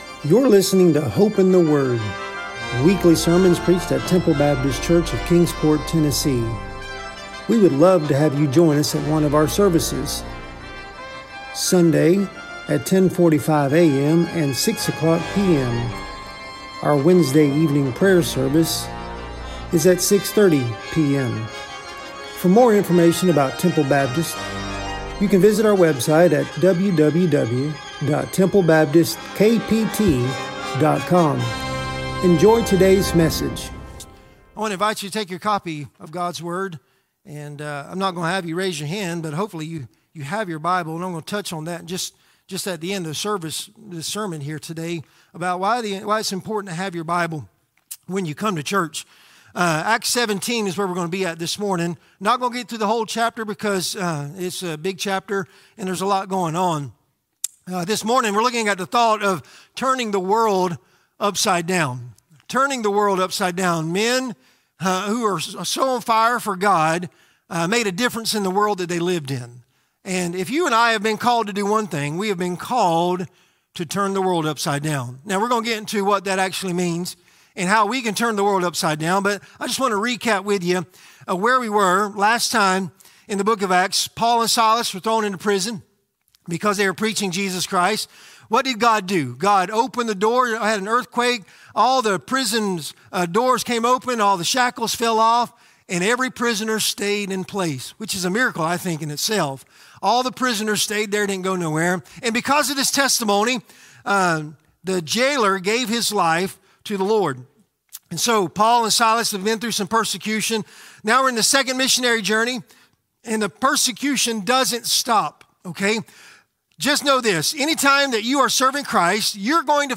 Sermons | Temple Baptist Church